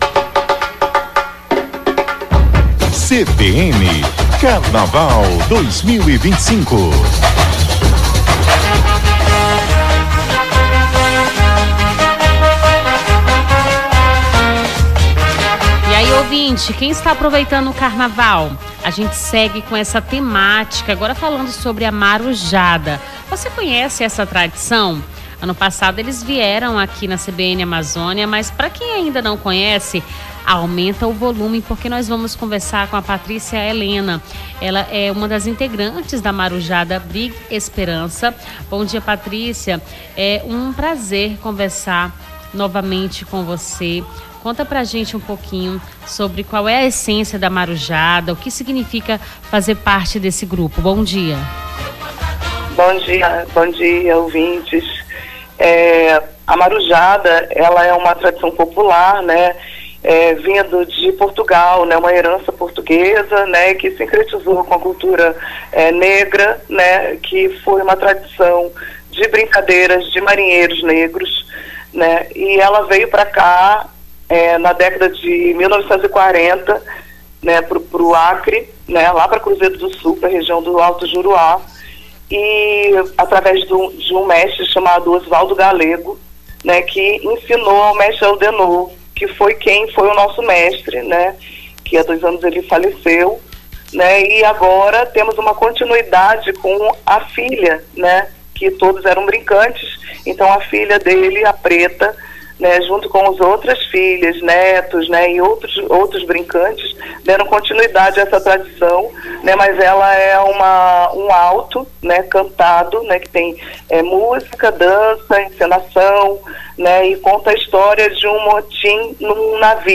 Nome do Artista - CENSURA - ENTREVISTA CLUBE DA MARUJADA (03-03-25).mp3